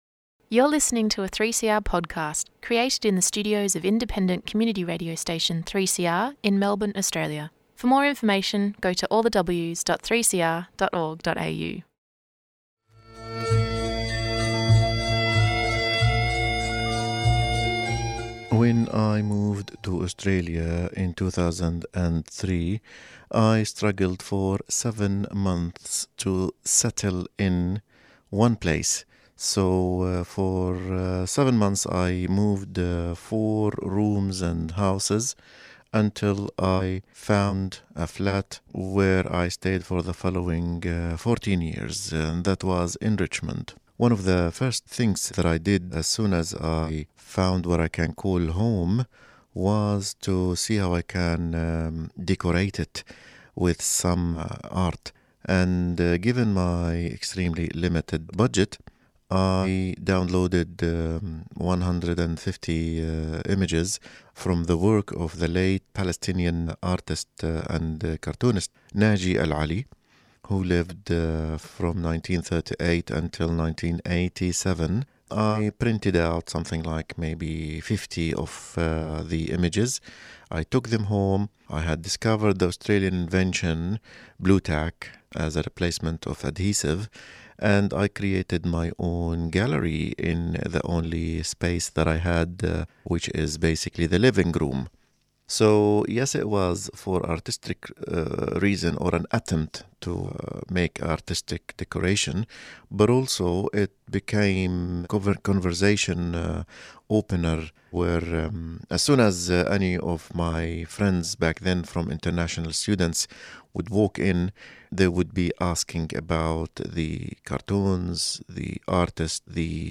Day 406 - Commentary and updates. Conversation